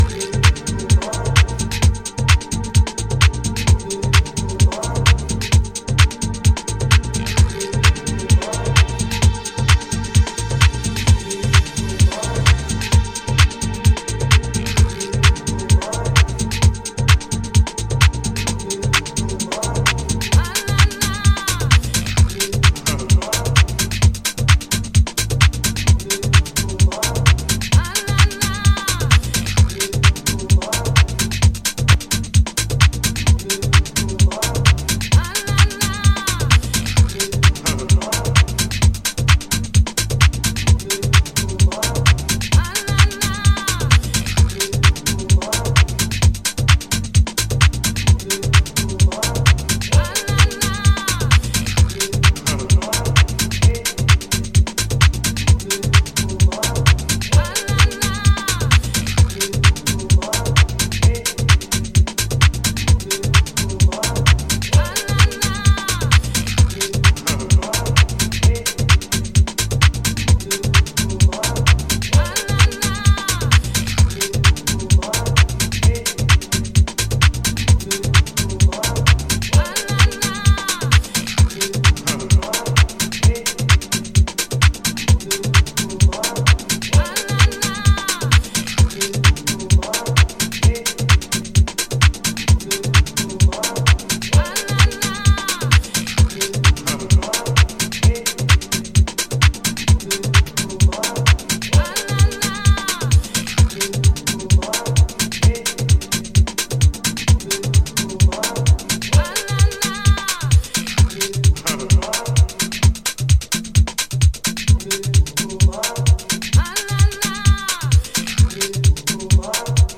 武骨に反復するファットなベースと浮遊感溢れるパーカッションフレーズでズブズブにハメる